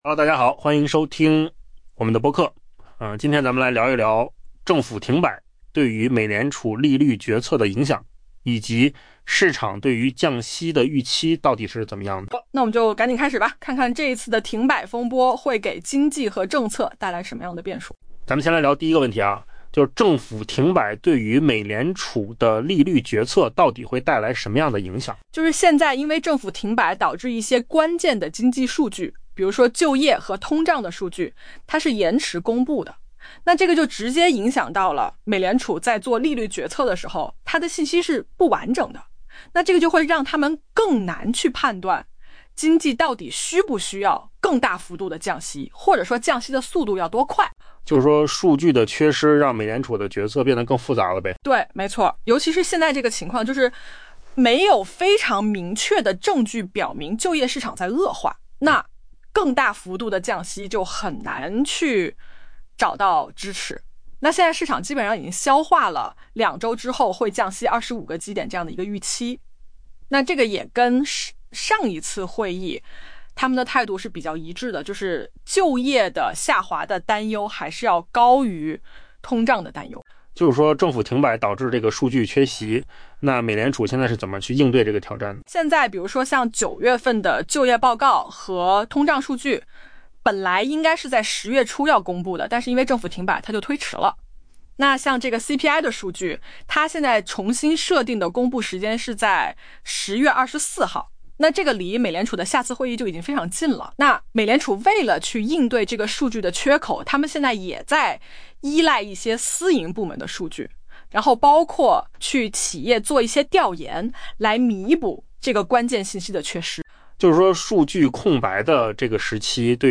AI 播客：换个方式听新闻 下载 mp3 音频由扣子空间生成 「美联储传声筒」、《华尔街日报》 记者 Nick Timiraos 周四撰文称，一场持续的政府停摆正使美联储官员面临在缺乏关键经济数据的情况下作出下一次利率决策的可能性，而这些数据本可平息关于降息幅度与速度的激烈争论。